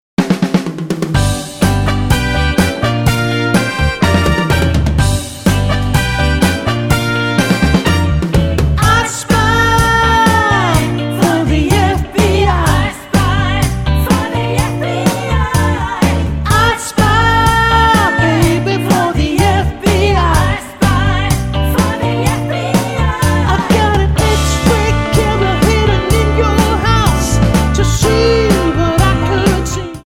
Tonart:G Multifile (kein Sofortdownload.
Die besten Playbacks Instrumentals und Karaoke Versionen .